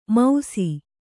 ♪ mausi